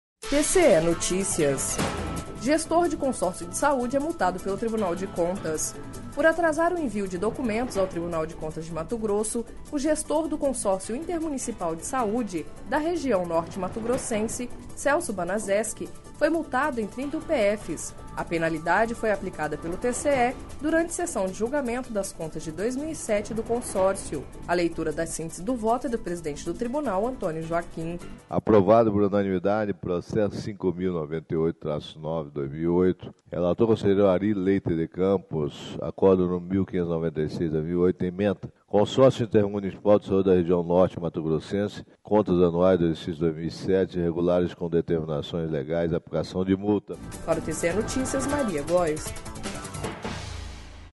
A penalidade foi aplicada pelo TCE durante sessão de julgamento das contas de 2007 do Consórcio./ A leitura da síntese do voto é do presidente do Tribunal, Antonio Joaquim.// Sonora: Antônio Joaquim – conselheiro presidente do TCE-MT